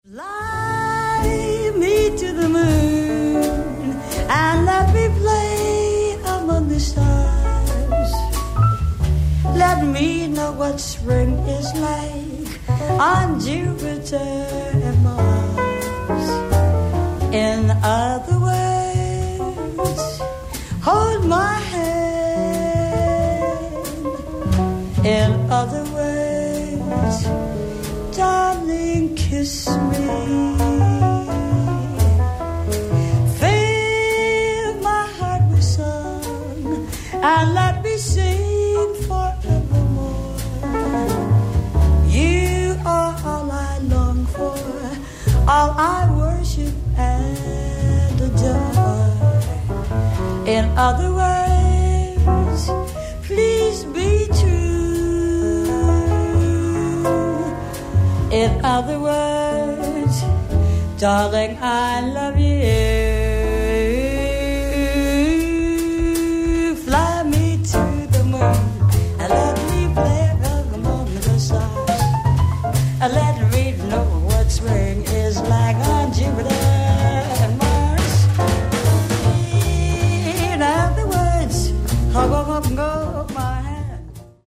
ライブ・アット・アンティーブ・ジャズ、ジュアン・レ・パン 97/23,24/1966
※試聴用に実際より音質を落としています。